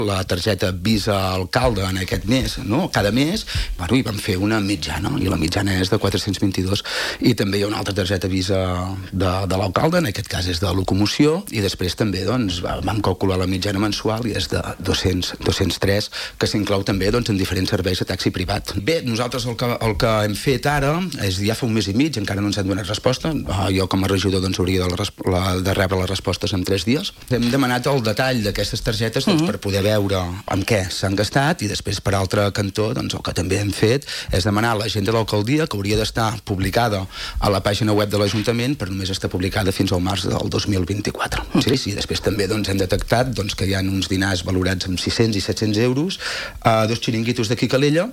Moment de l'entrevista d'ahir